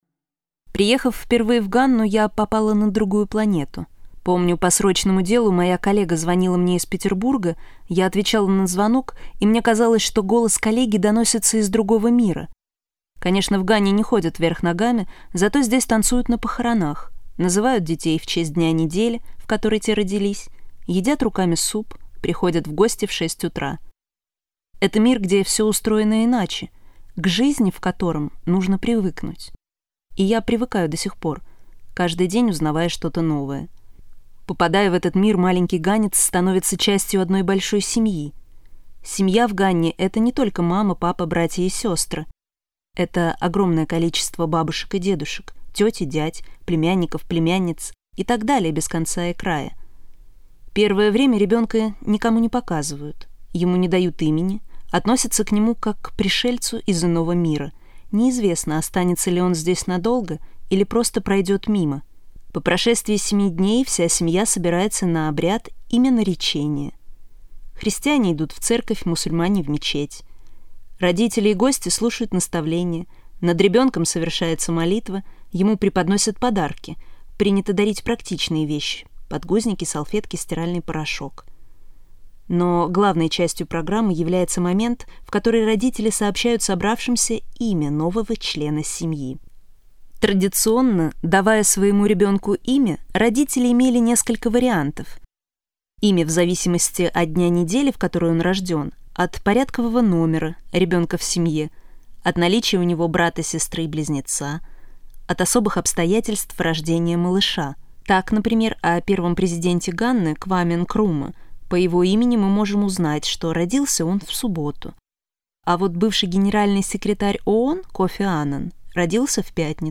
Аудиокнига Африка. Все тонкости | Библиотека аудиокниг